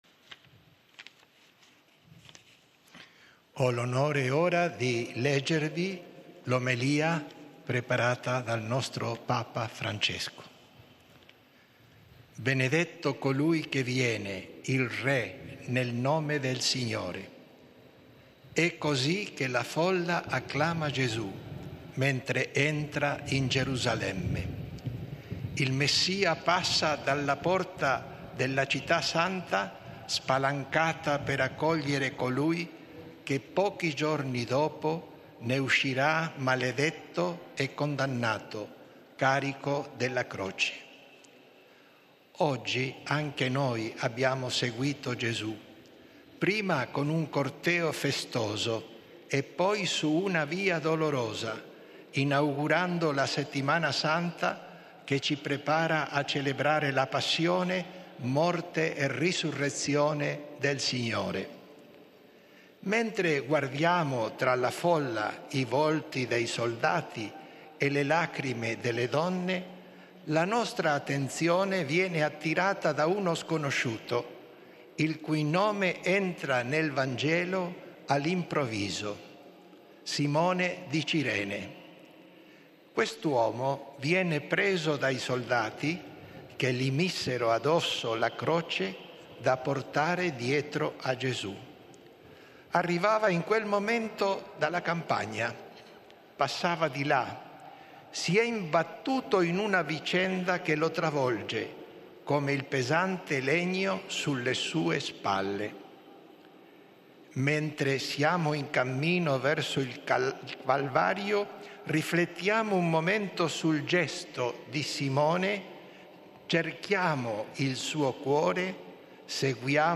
This podcast offers the public speeches of the Holy Father, in their original languages.